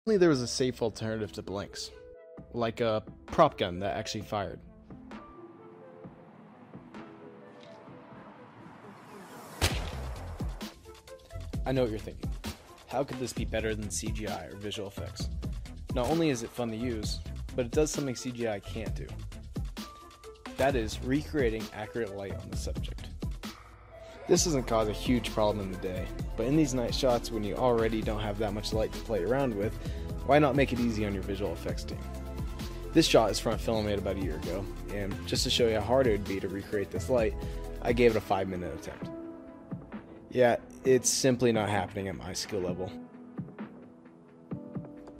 A prop gun that actually fires. This is much safer than blank ammunition and a more realistic alternative to adding a mussel flash in post.